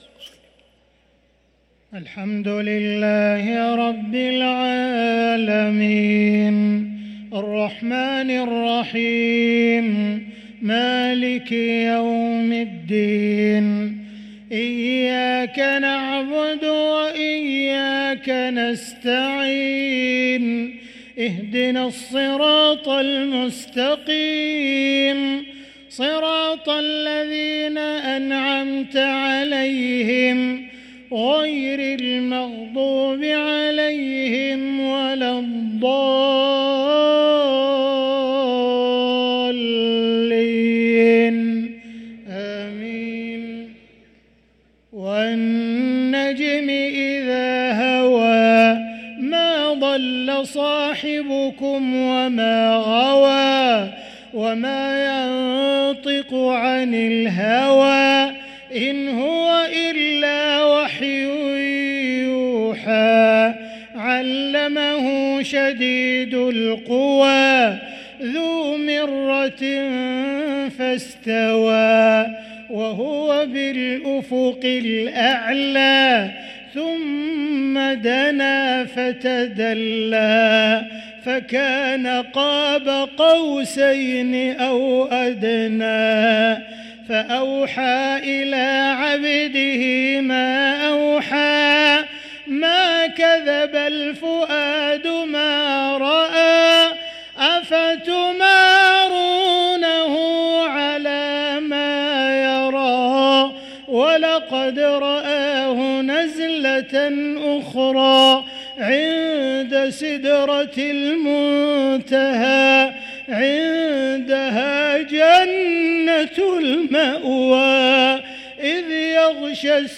صلاة العشاء للقارئ عبدالرحمن السديس 22 جمادي الآخر 1445 هـ